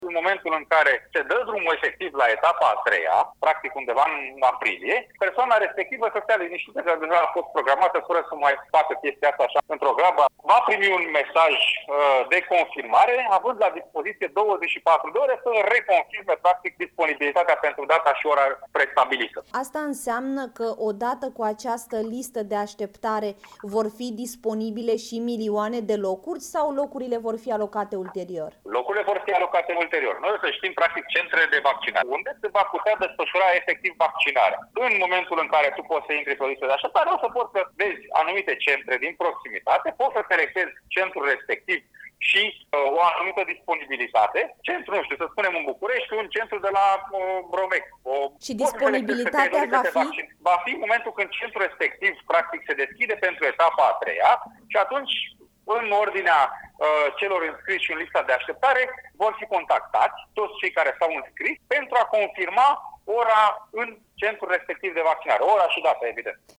Lista va fi disponibilă pe platforma de programare folosită și acum. Secretarul de stat în Ministerul Sănătății, Andrei Baciu, spune, la Europa FM, că înscrierea pe lista de așteptare nu este echivalentă cu programarea la vaccinare, dar este o rezervare a ordinii de imunizare:
01mar-11-Baciu-interviu-etapa-a-iii-a.mp3